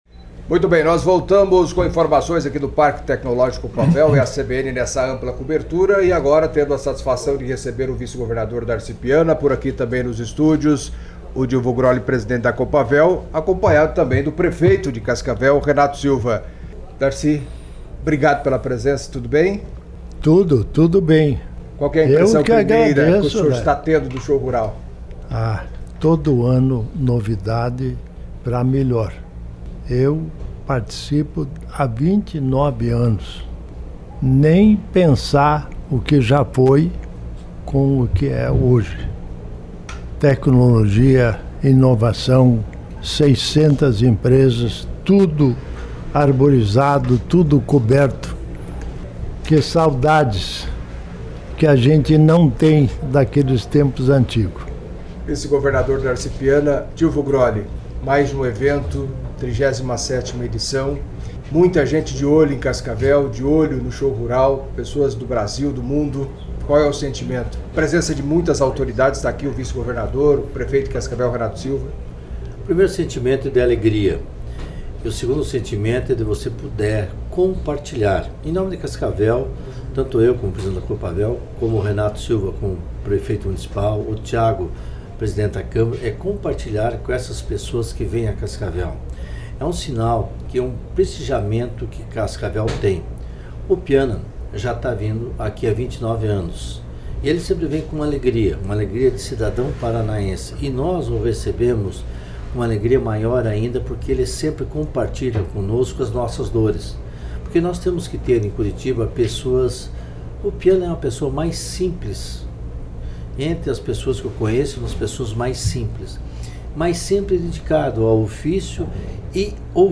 Show Rural 2025
14:02 Ouça DARCI PIANA, VICE-GOVERNADOR DO PARANÁ Darci Piana